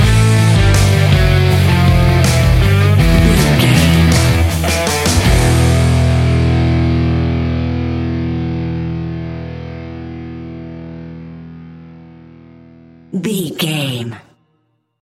Aeolian/Minor
hard rock
distortion
Rock Bass
heavy drums
distorted guitars
hammond organ